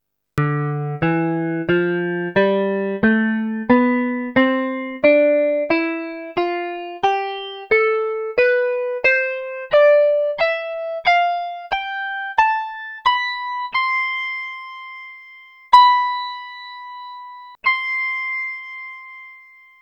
MTC Sync test